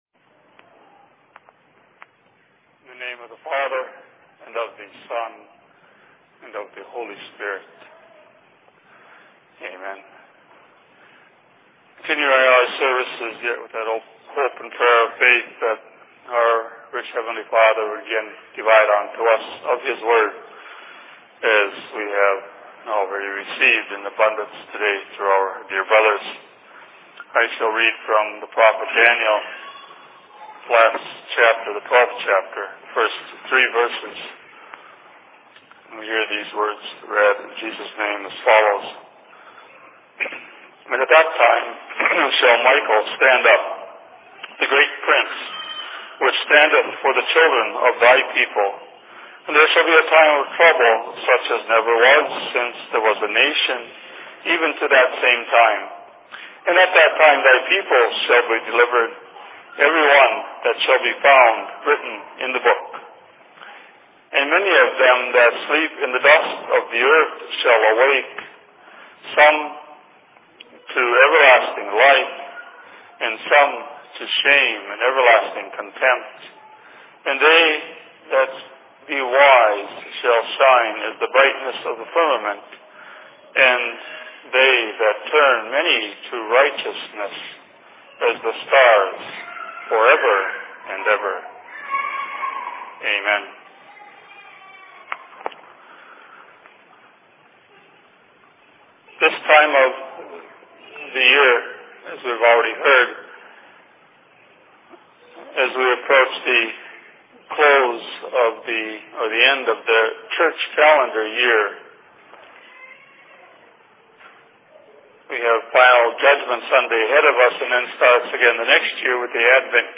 Sermon in Minneapolis 19.11.2006